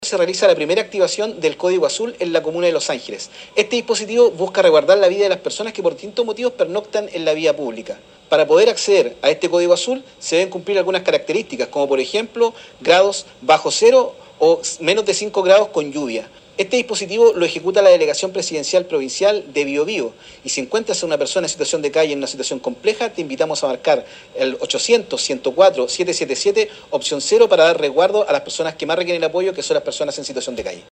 Así lo indicó el seremi de Desarrollo Social y Familia en Bio Bío, Hedson Díaz.